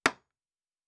Nail 6_4.wav